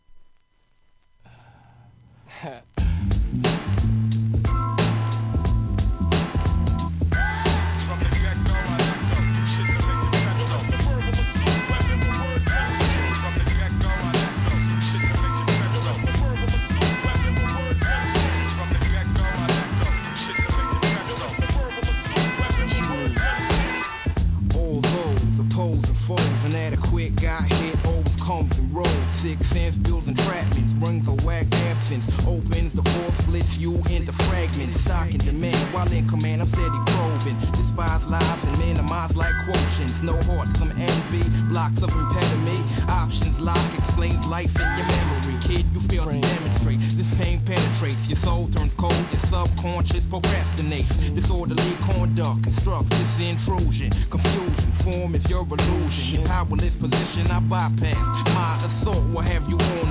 HIP HOP/R&B
カナダ産極上DOPEアングラ！！ タイトなビートにホーンが渋い